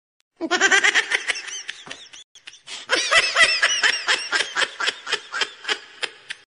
Baby Laughing Meme